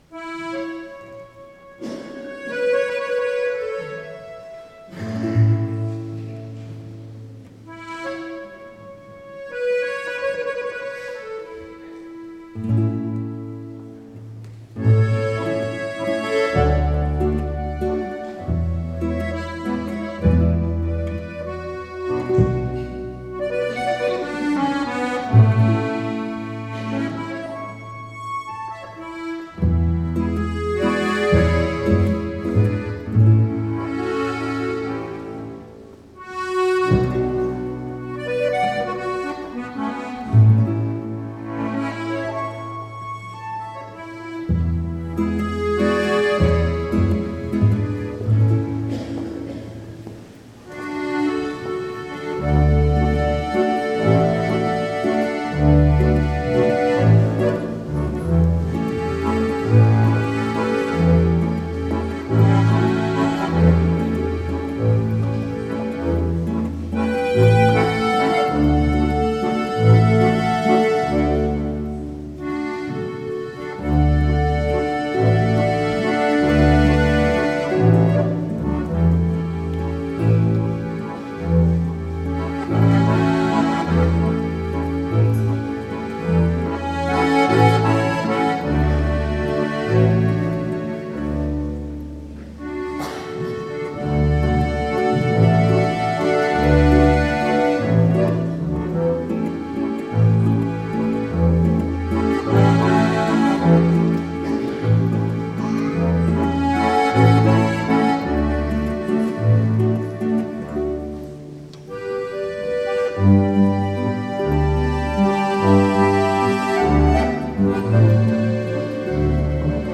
Ziehharmonika, Gitarre und Kontrabass ein sehr angenehmes und familiäres Flair
Die Musikstücke aus dem Gottesdienst (einzelne Titel mittels Mausklick abspielen) Einzug Eröffnung Kyrie Antiphon Gabenbereitung Sanctus Vater Unser Kommunion Danklied Auszug